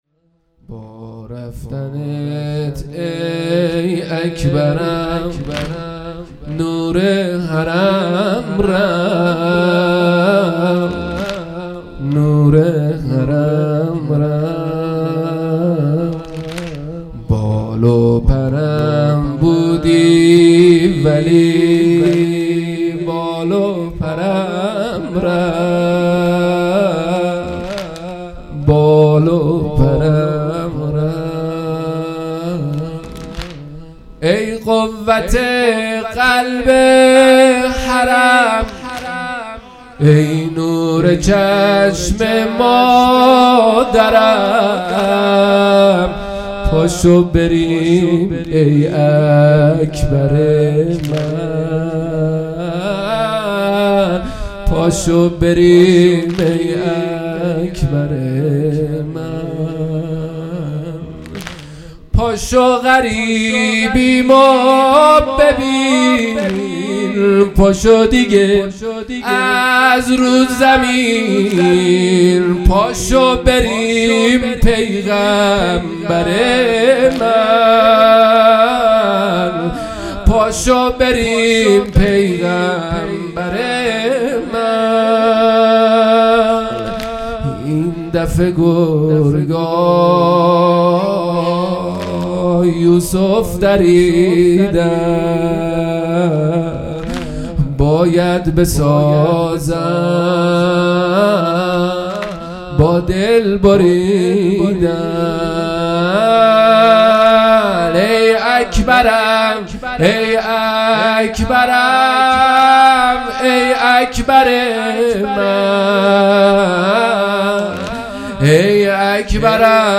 هیئت مکتب الزهرا(س)دارالعباده یزد
0 0 واحد | با رفتند ای اکبرم نور حرم رفت مداح